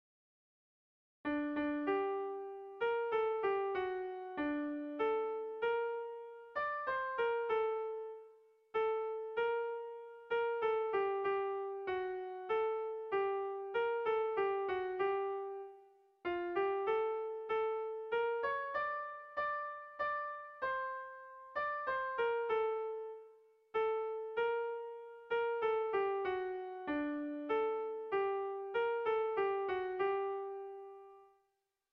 Zortziko txikia (hg) / Lau puntuko txikia (ip)
A1A2BA2